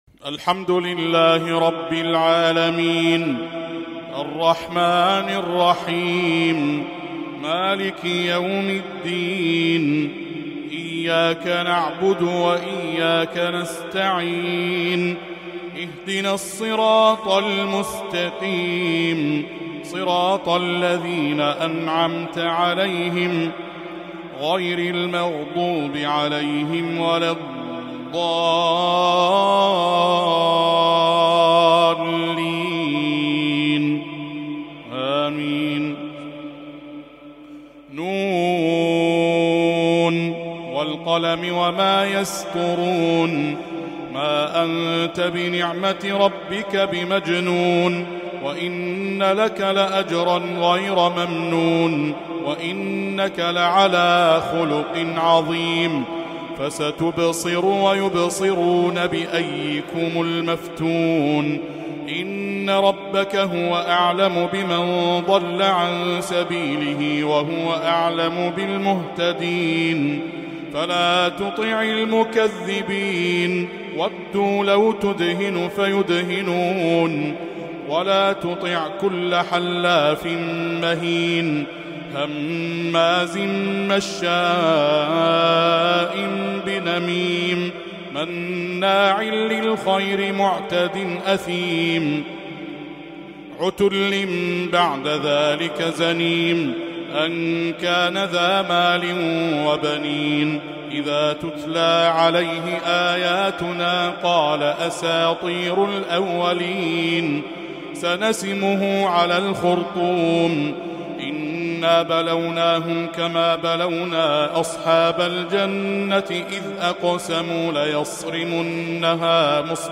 تلاوة شجية لسورة القلم